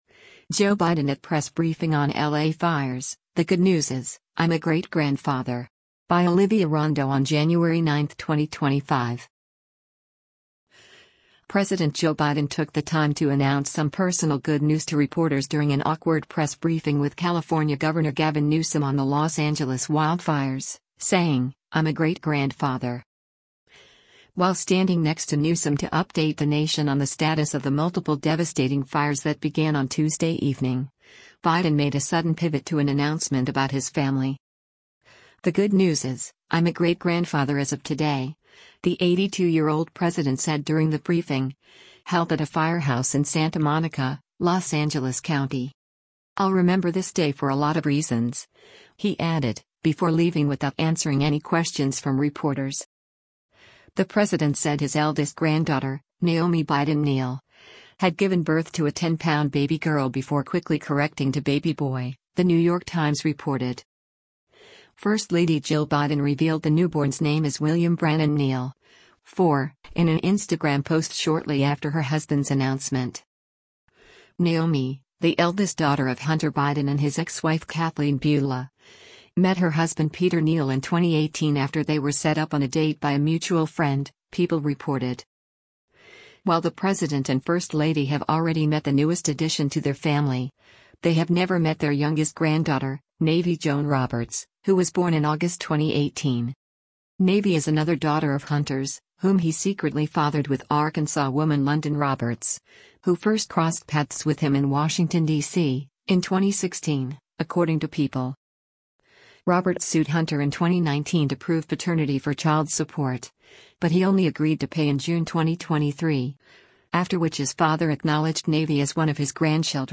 Joe Biden at Press Briefing on LA Fires: 'The Good News Is, I'm a Great-Grandfather!'
President Joe Biden took the time to announce some personal “good news” to reporters during an awkward press briefing with California Gov. Gavin Newsom on the Los Angeles wildfires, saying, “I’m a great-grandfather!”
“The good news is, I’m a great-grandfather as of today,” the 82-year-old president said during the briefing, held at a firehouse in Santa Monica, Los Angeles County.